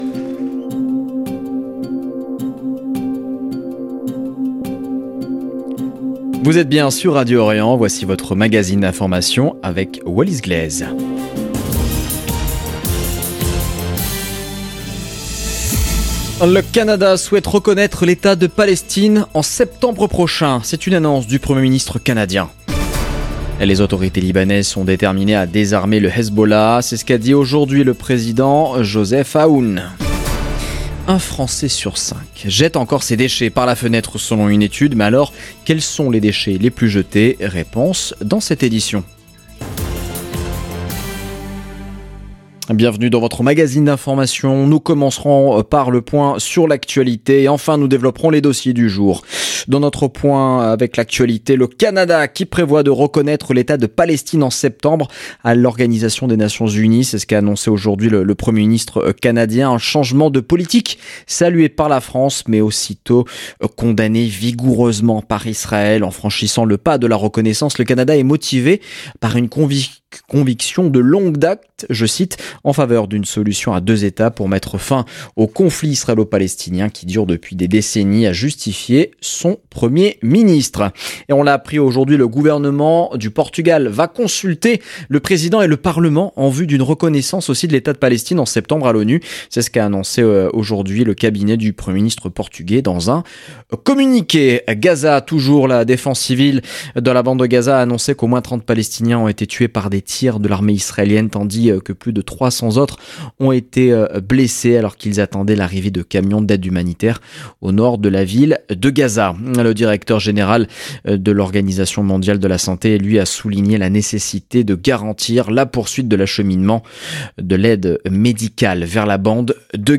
Magazine d'information de 17H du 31 juillet 2025